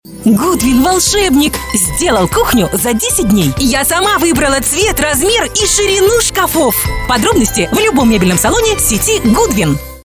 Жен, Рекламный ролик/Зрелый
Микрофон: RODE NT2-A, звуковая карта: Focusrite Scarlett 2Pre USB, профессиональная студия.